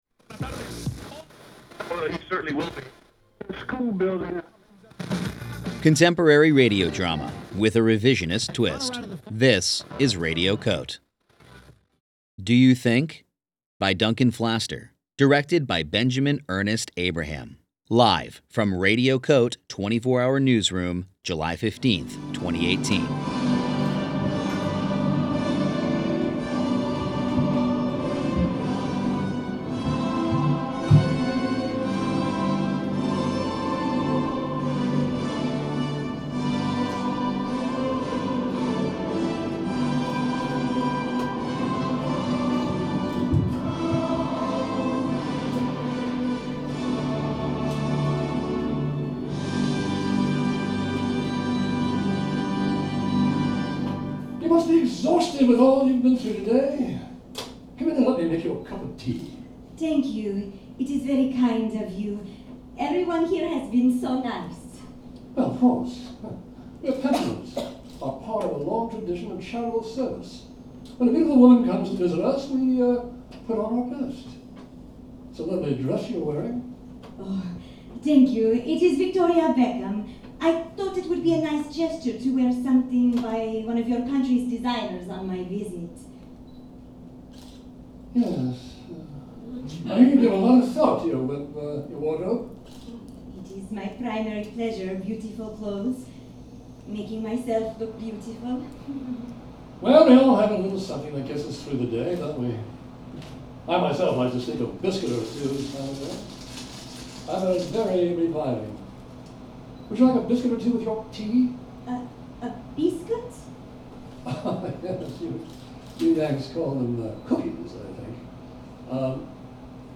performed live at UNDER St. Mark’s for Radio COTE: 24-hour Newsroom, July 15, 2018